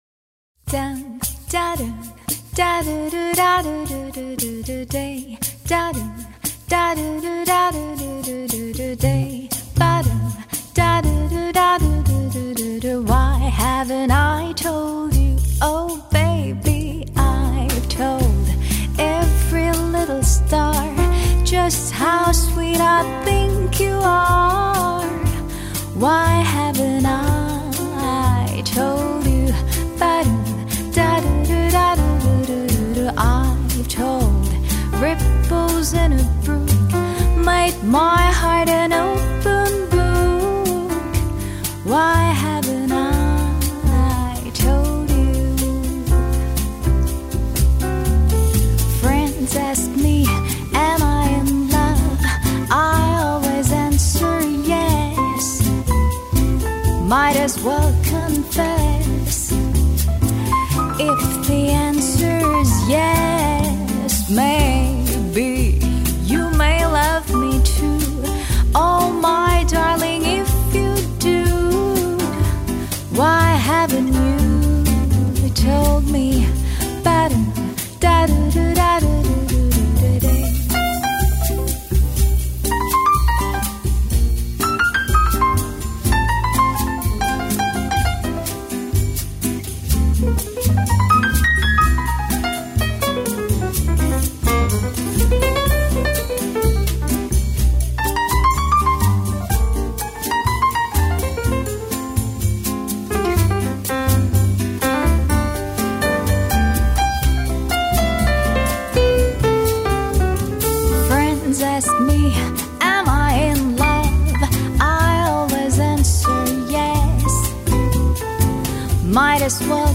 由钢琴、钢片琴、低音、鼓以及她自己的歌声组成